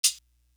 Bumpy Hat.wav